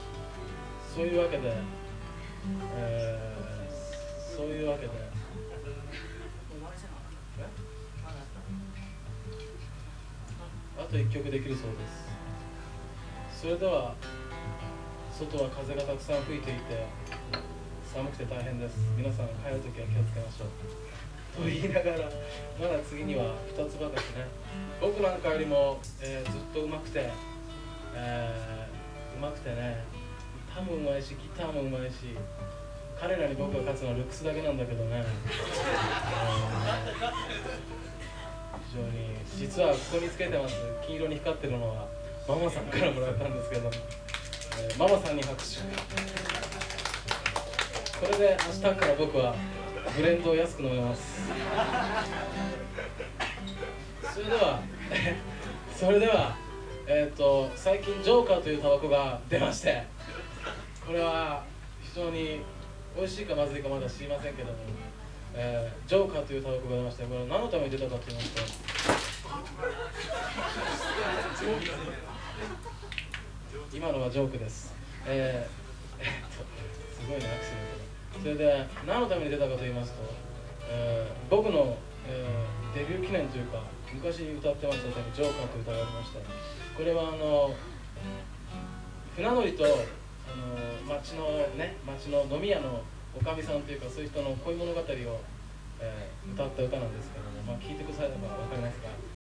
1980年武蔵新城「珈琲専科　亜豆」LIVE